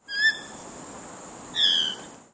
Dusky Moorhen
They have a more quiet call.
Click below to hear their call:
Dusky-Moorhen-2.mp3